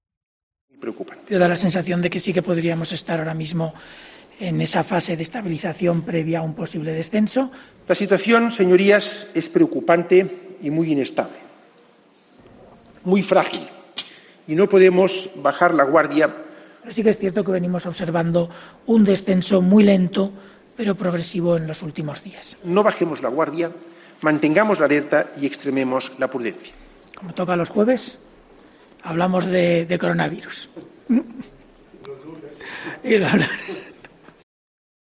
En este montaje se puede apreciar como ambos han manifestado puntos de vista radicalmente opuestos sobre la situación de la pandemia en nuestro país. Al final de este montaje sonoro, se escucha cómo Fernando Simón se echa a reír en complicidad con los periodistas.
Risas de Fernando Simón